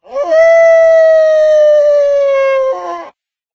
mob / wolf / howl1.ogg
howl1.ogg